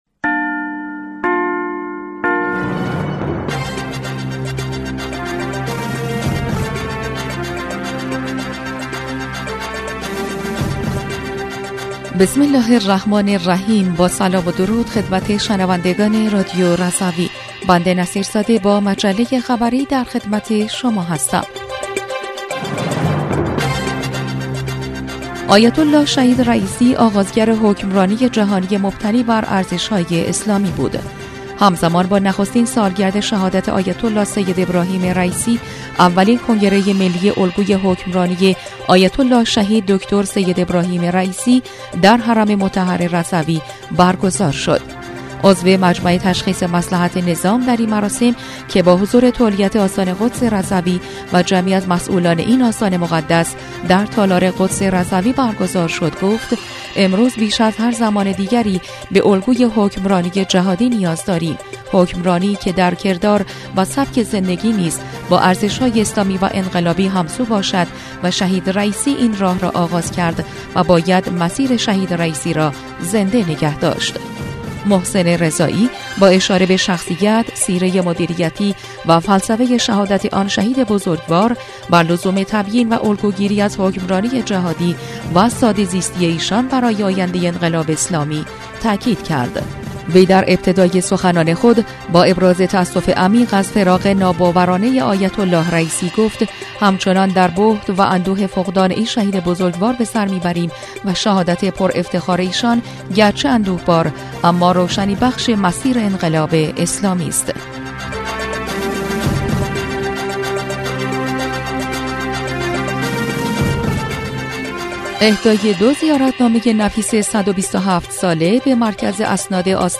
بسته خبری جمعه ۲ خردادماه رادیو رضوی/